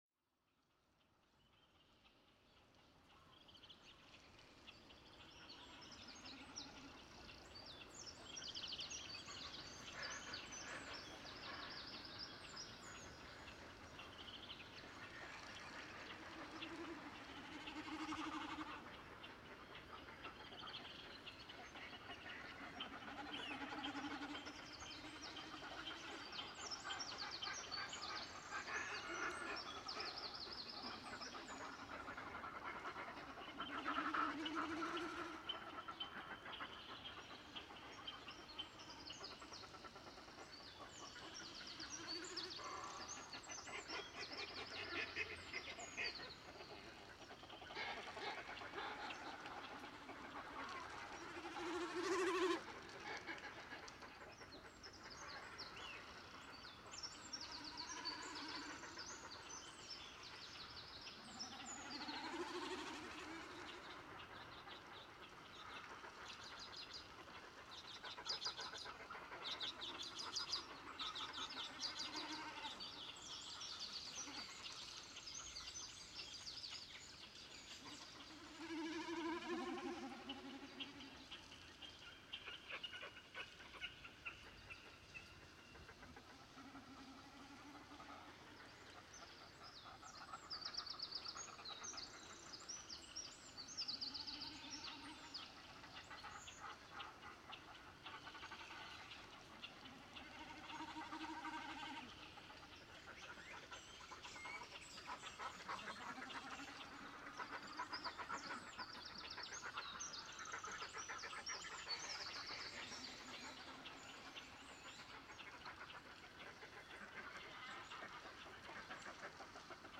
The weather was rather cold, with light drizzle rain.
Calls, yell and sheers from audience sounded fantastic in the echo from the surrounding cliffs. Mixed with the birdsong from the forest and the cliffs above it sounded like I was in another world.
Soon I noticed a boring noise in the background from petrol power generator so the recording was shorter than I hoped .
I heard someone in the crowd playing on Mbira thump piano. It came from female in traditional dress.